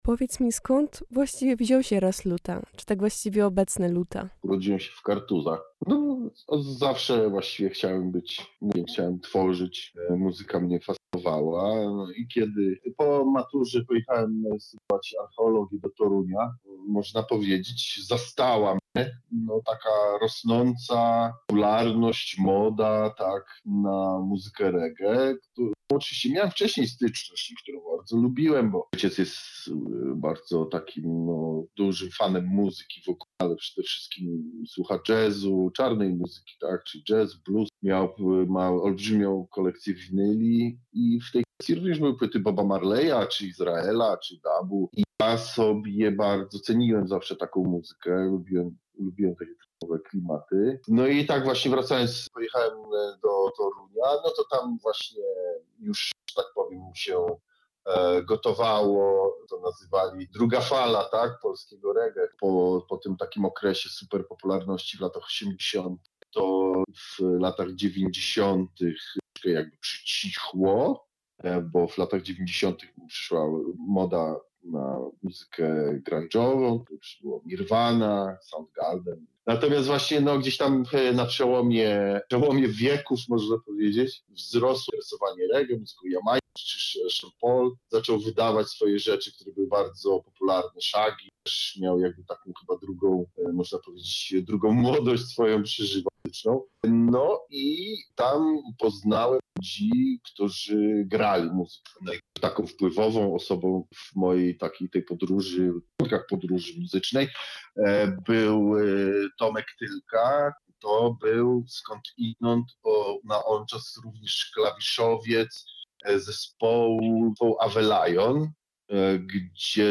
Co się stało z polskim reggae?